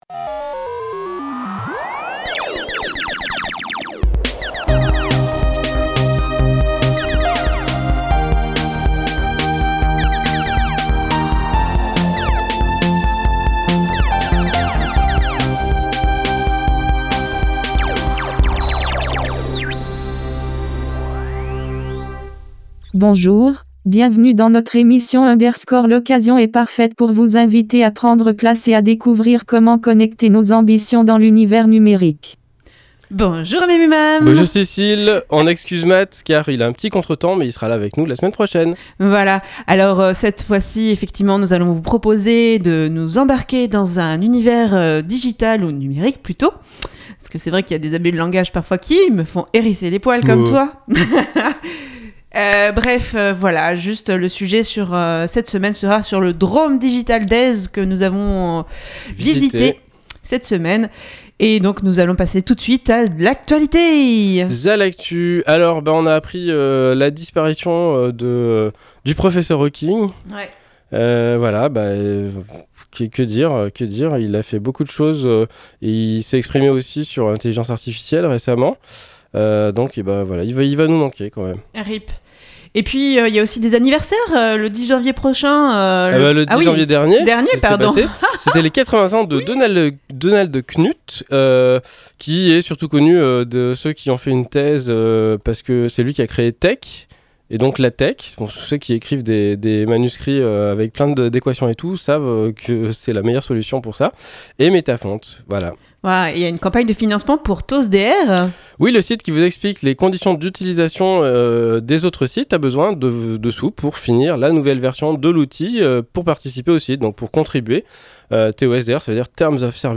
L'Internet des Objets aux Drôme Digital Days De l'actu, une pause chiptune, un sujet, l'agenda, et astrologeek !